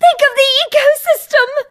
bea_die_vo_01.ogg